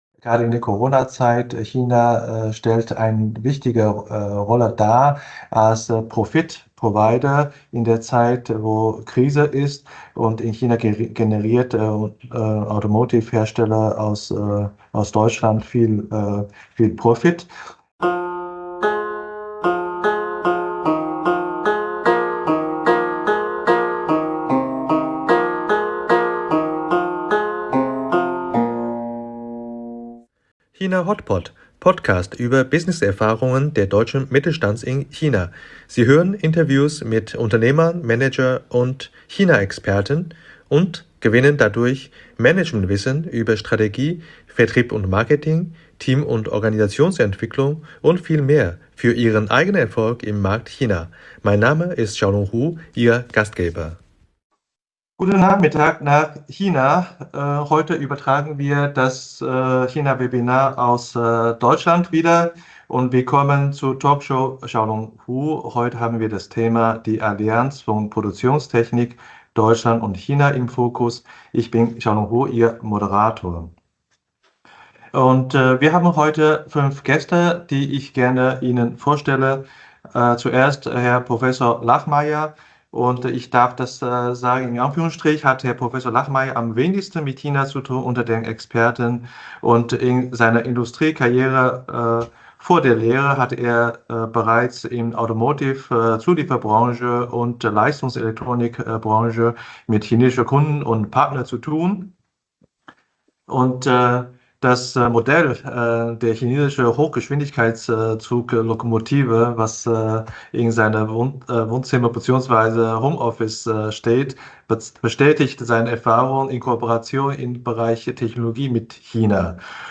Mit diesem Podcast-Programm möchte ich die Vielfalt und Herausforderung des chinesischen Marktes zeigen. Im Interview erzählen erfahrene China-Manager aus Deutschland ihre eigene Geschichten.